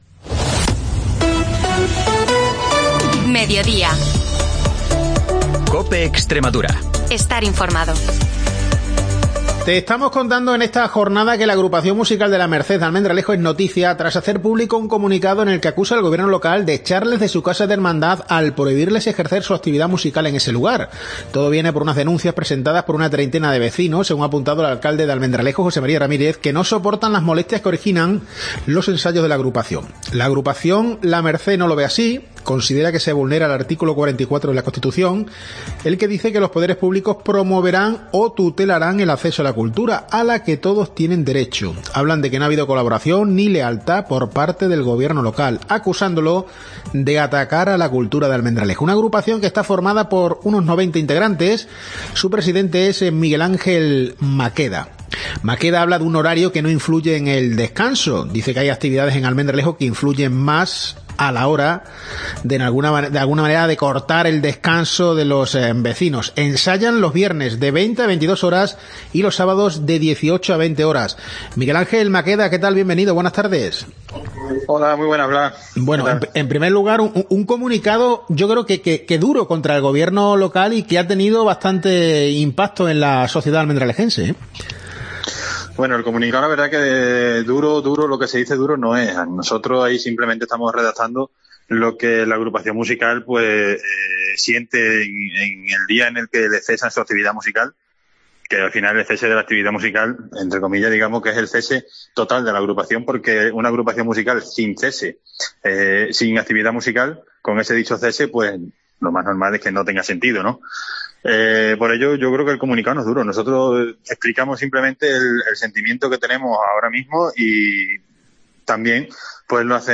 AUDIO: Información y entrevistas de Almendralejo-Tierra de Barros y Zafra-Río Bodión, de lunes a jueves, de 13.50 a 14 horas